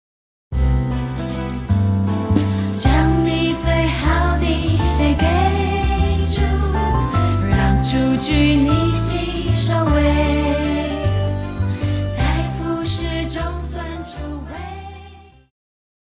Christian
Pop chorus,Children Voice
Band
Hymn,POP,Christian Music
Voice with accompaniment